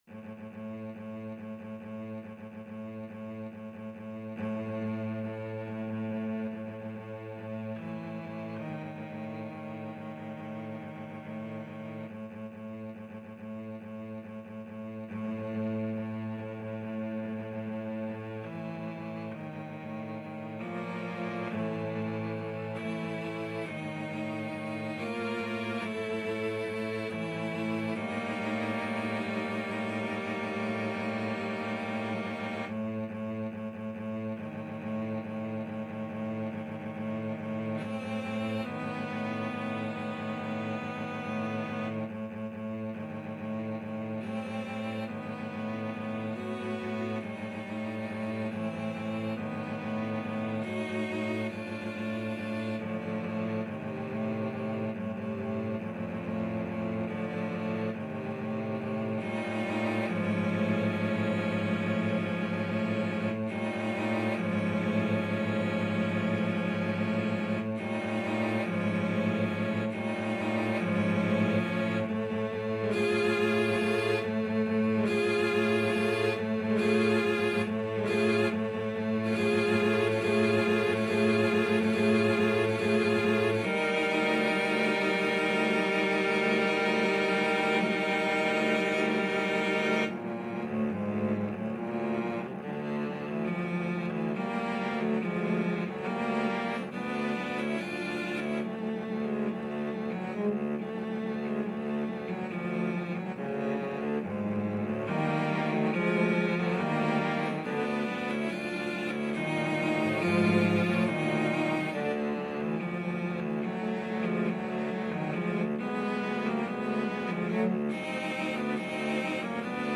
5/4 (View more 5/4 Music)
Allegro = 140 (View more music marked Allegro)
Cello Quartet  (View more Intermediate Cello Quartet Music)
Classical (View more Classical Cello Quartet Music)